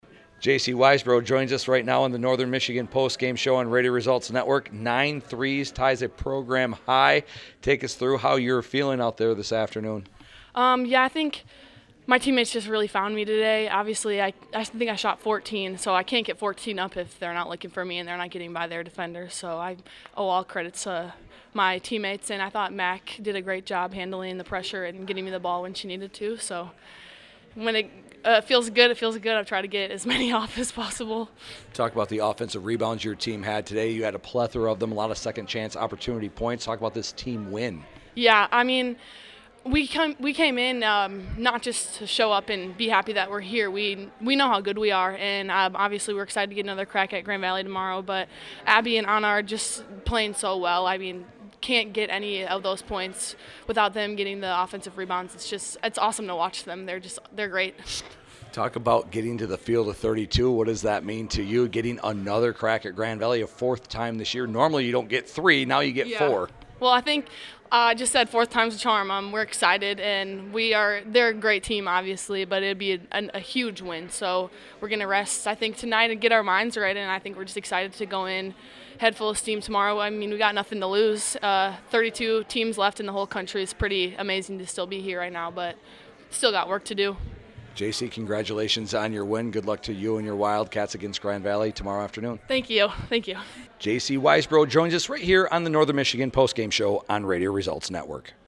post-game comments